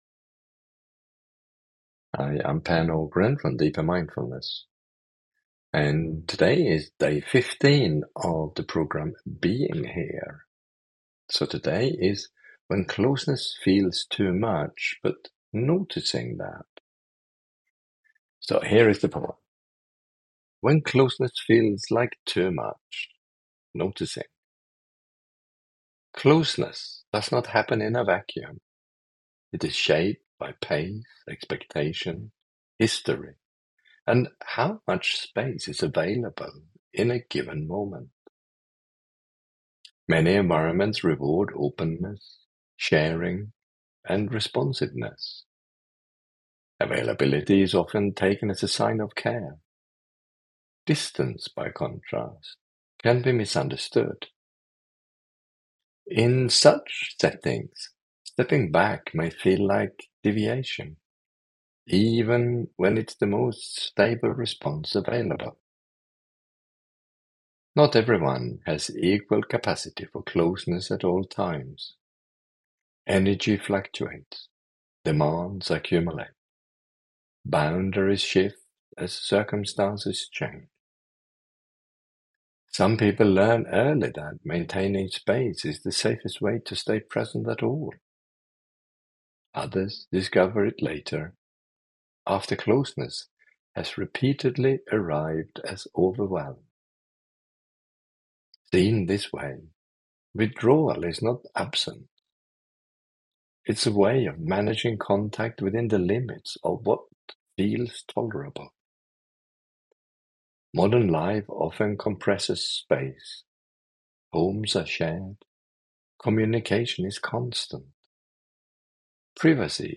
Poem_Being-Here-Poem-Day-15.mp3